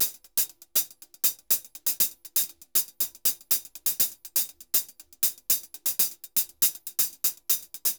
HH_Candombe 120_2.wav